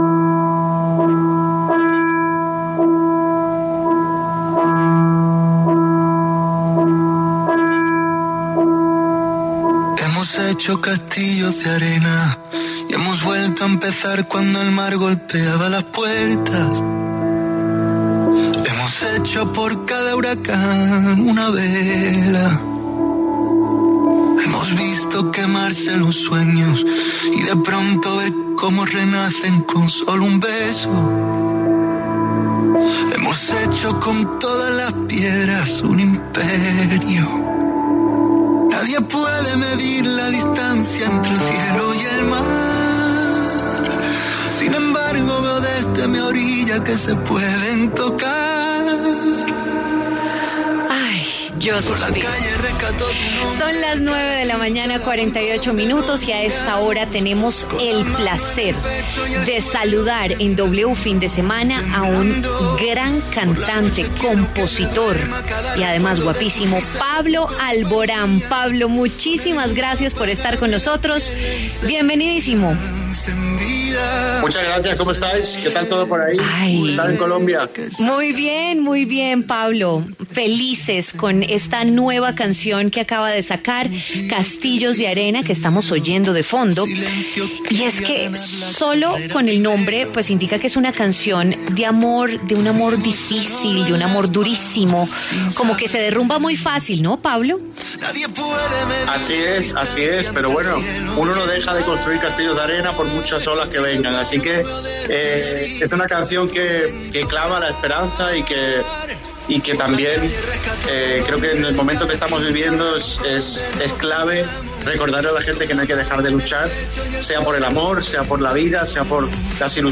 El cantante español pasó por los micrófonos de W Fin de Semana para dar detalles de este nuevo trabajo musical.